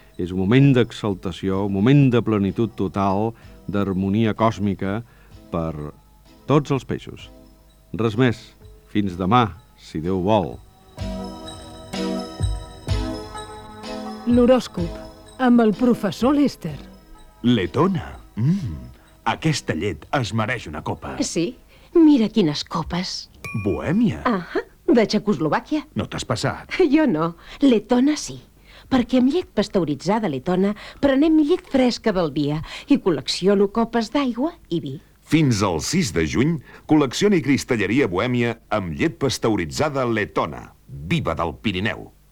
L'horòscop i publicitat
Banda FM